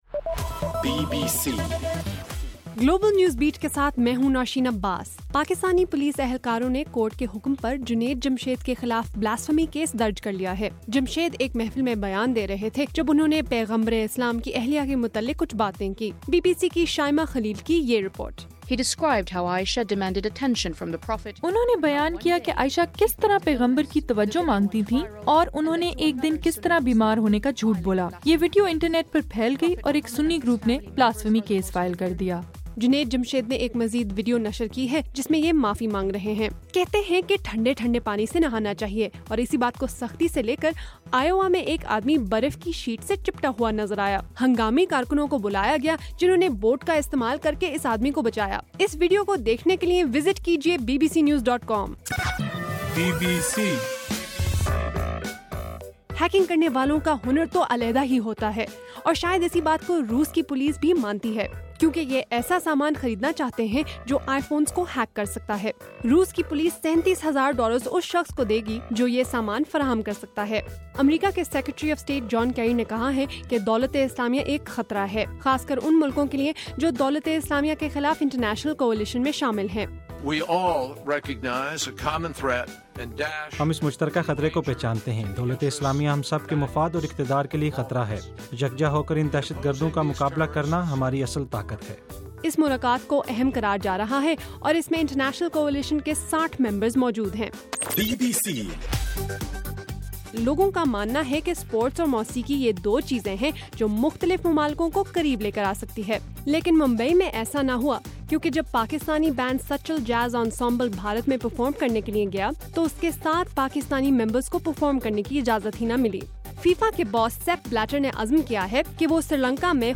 دسمبر3 : رات 8 بجے کا گلوبل نیوز بیٹ بُلیٹن